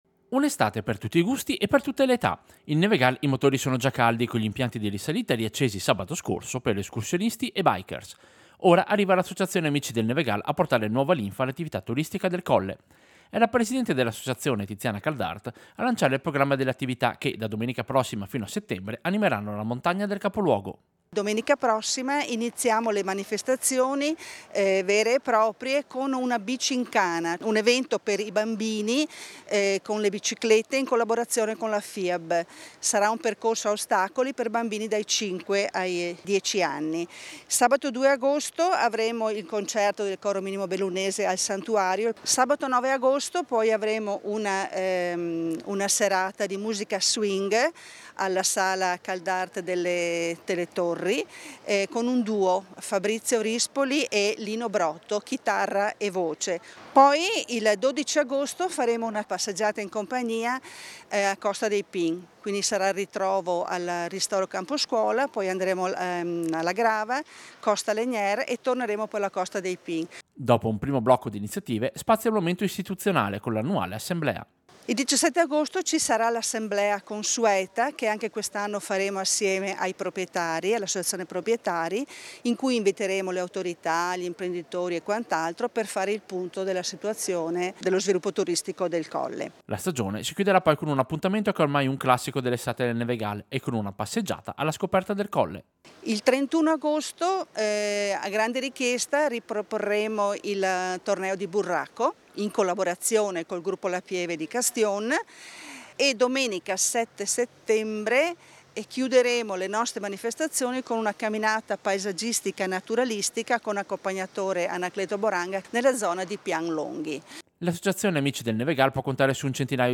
Servizio-Programma-amici-Nevegal-2025.mp3